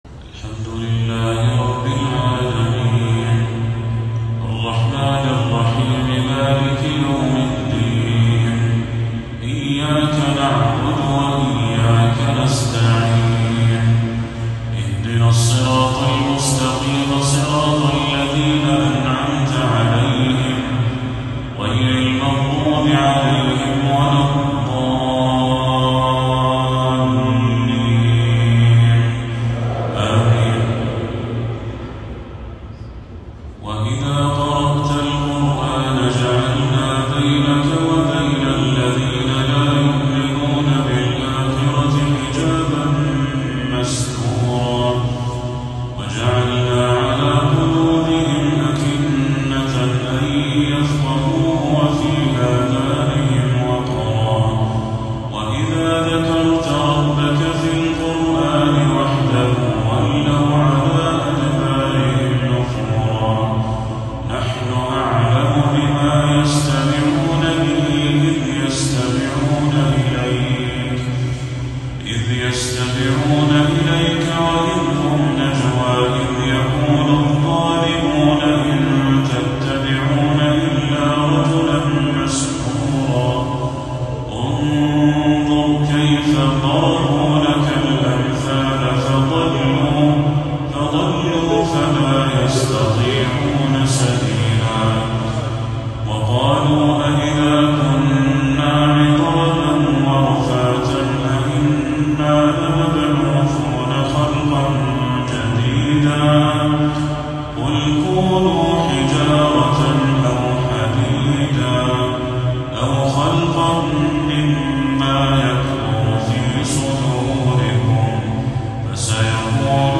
تلاوة أخَّاذة بأداء لا يوصف من سورة الإسراء للشيخ بدر التركي | عشاء 22 صفر 1446هـ > 1446هـ > تلاوات الشيخ بدر التركي > المزيد - تلاوات الحرمين